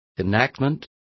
Also find out how promulgacion is pronounced correctly.